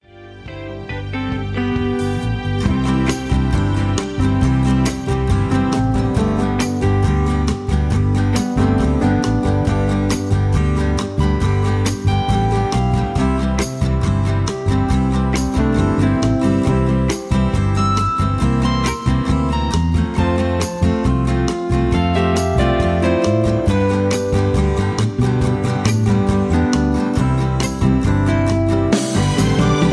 easy listening, country music